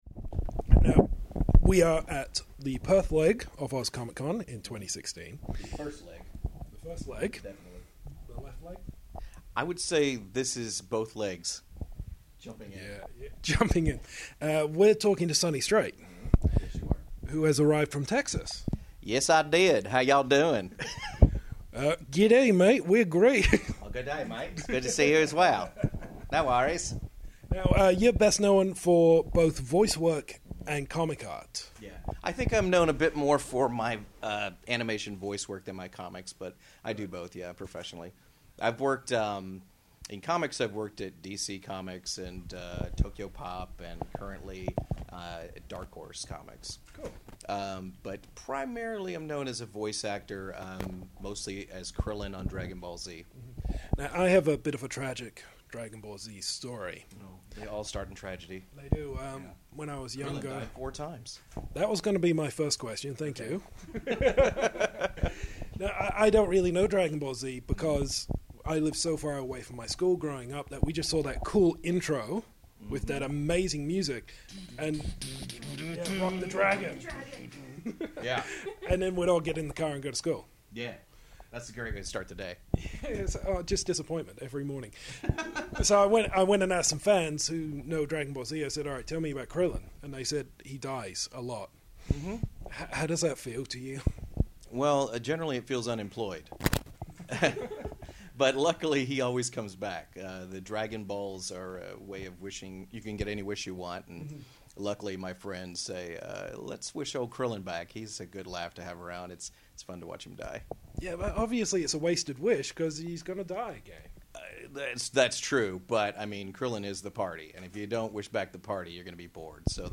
Now for our third Oz Comic-Con interview, where we sat down with the very funny, quite loquacious Sonny Strait! Best known as the voice of Krillin on Dragonball Z and his work on Elfquest, we spoke about blank puppets, pregnant blow up dolls and not the live action film.